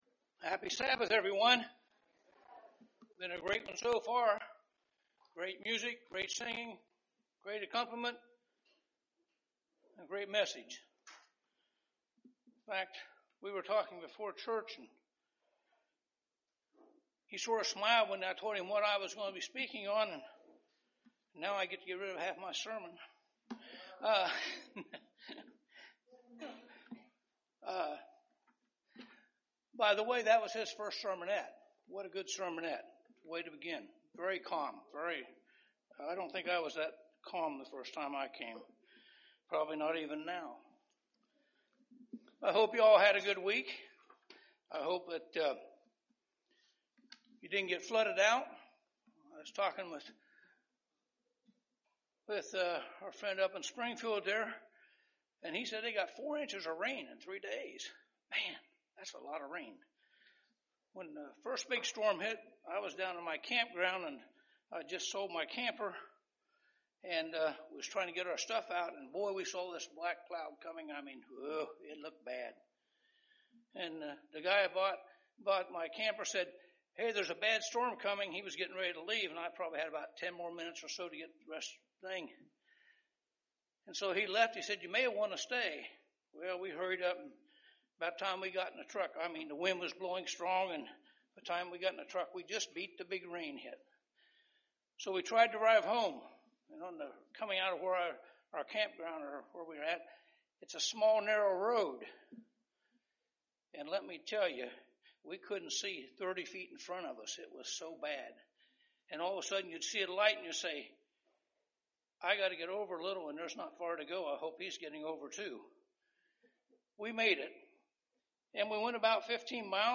Sermon
Given in Dayton, OH